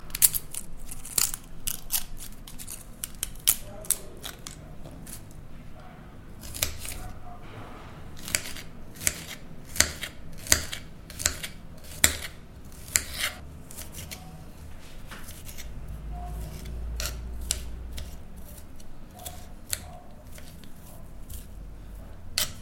Звук жарки чеснока на раскаленной сковороде